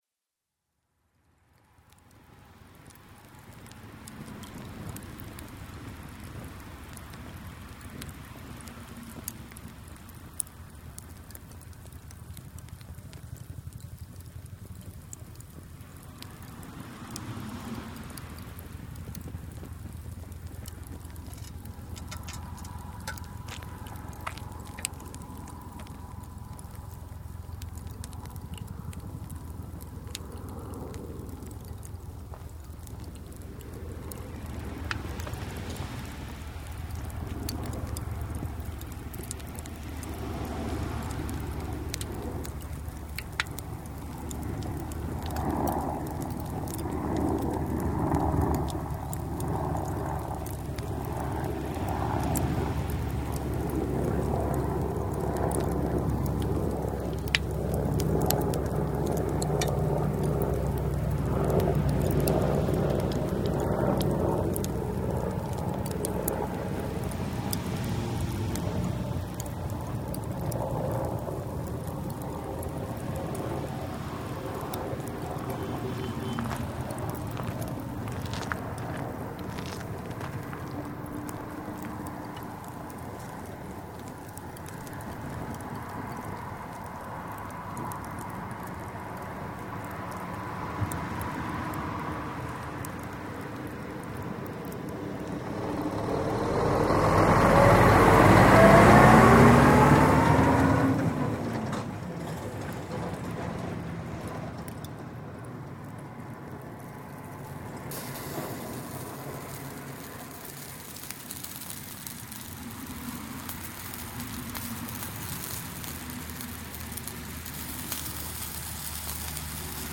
Roadside BBQ